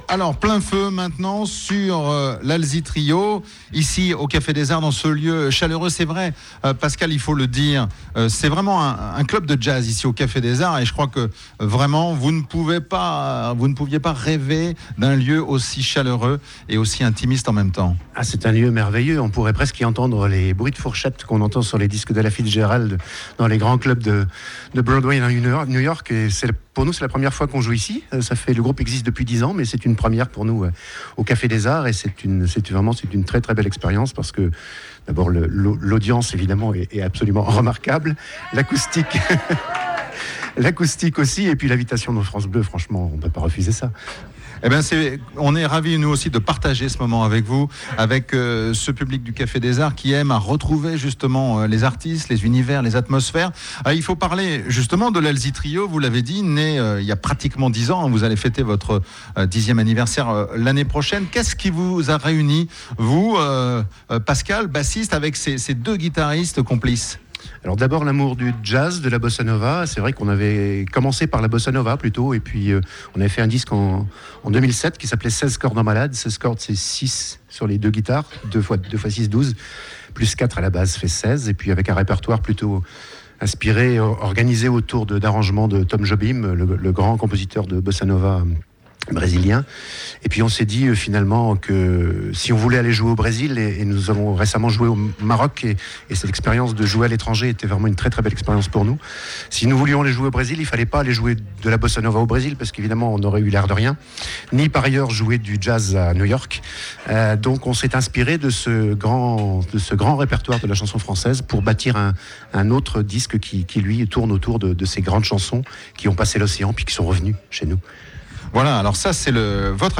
La seconde partie (8 minutes) reprend l’interview qui a suivi le concert :